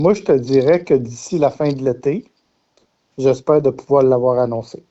Le député Donald Martel a assuré qu’il continue de travailler sur ce dossier.